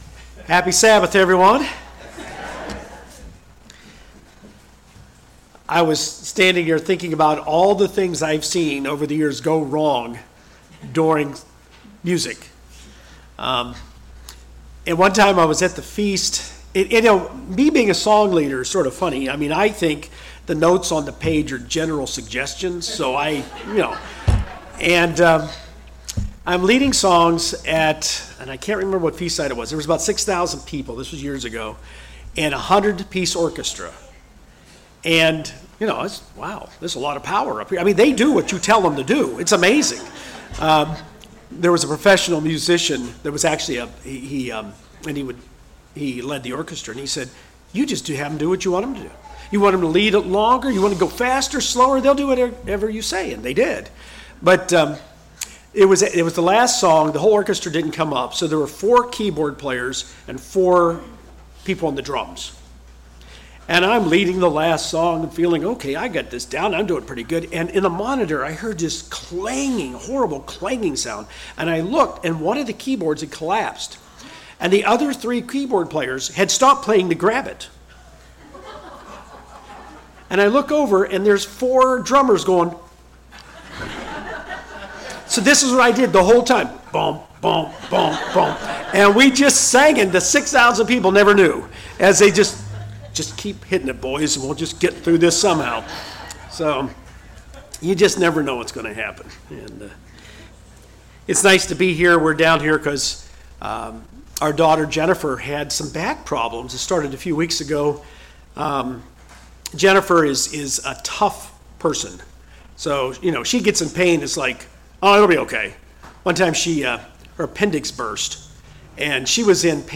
Every Christian will at times in their life have a crises of faith. In this message we'll look at 3 kinds of crises we can have in our faith and why we have them.